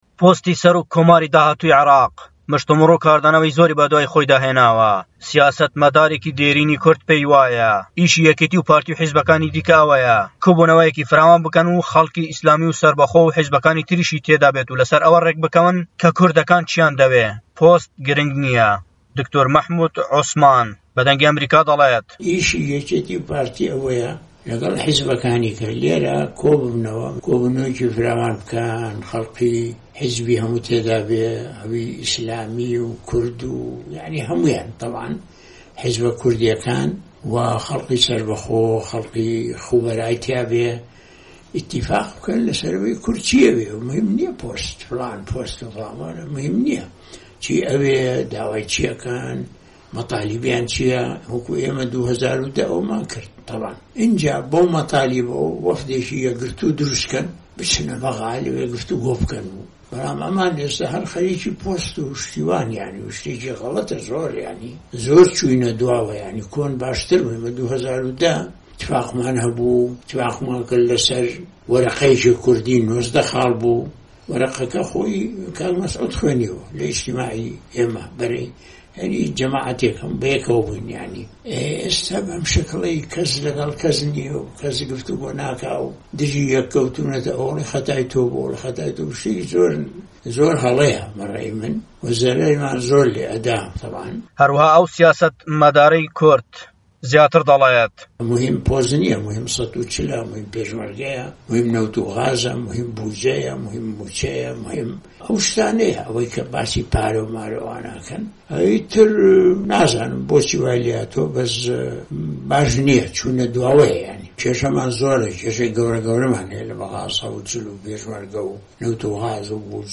وتوێژ لەگەڵ دکتۆر مەحمود عوسمان